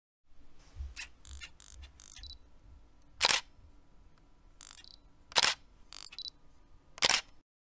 دانلود آهنگ عکس گرفتن دوربین عکاسی 3 از افکت صوتی اشیاء
دانلود صدای عکس گرفتن دوربین عکاسی 3 از ساعد نیوز با لینک مستقیم و کیفیت بالا
جلوه های صوتی